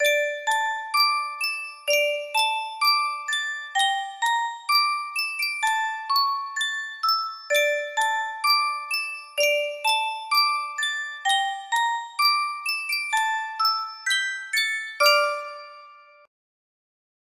Yunsheng Speldosa - Uti vår hage 5485 music box melody
Full range 60